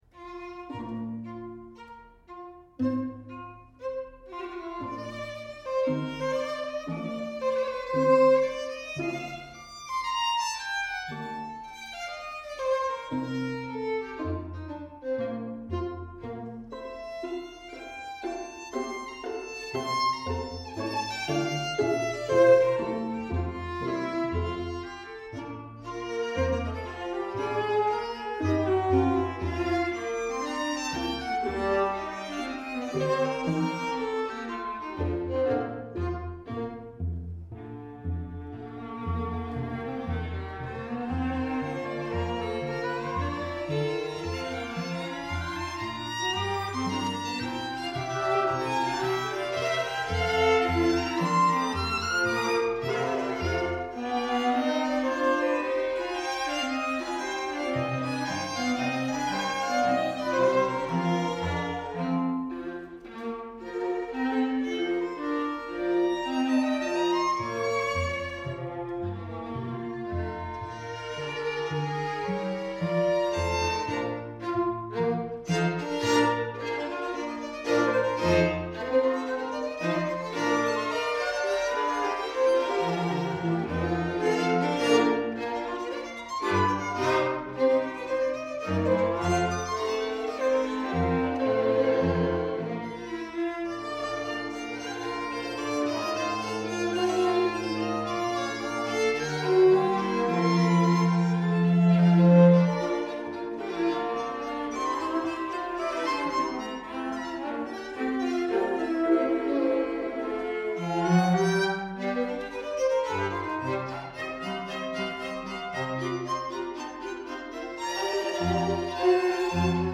Soundbite 2nd Movt
For 2 Violins, 2 Violas and Violoncello